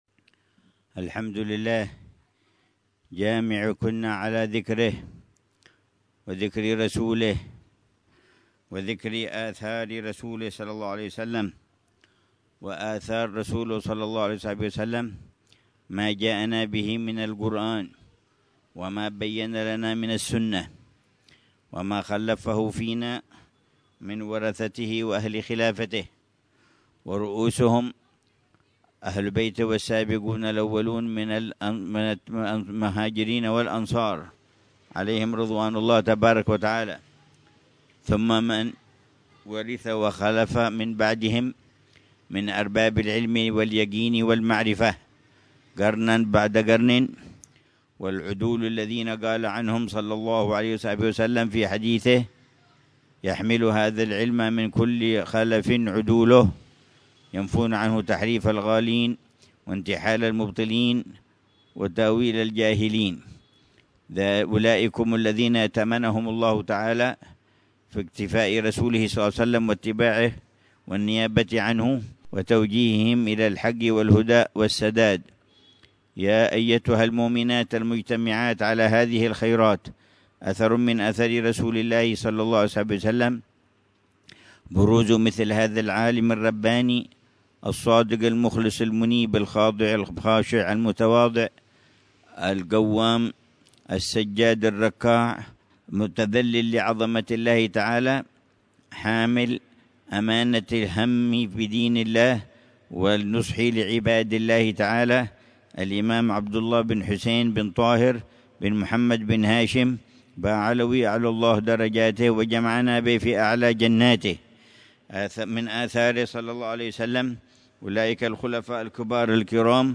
محاضرة الحبيب عمر بن محمد بن حفيظ للنساء في ذكرى حولية العلامة الإمام الحبيب عبد الله بن حسين بن طاهر في مدينة تريم، عصر الخميس 17 ربيع الثاني 1447هـ بعنوان: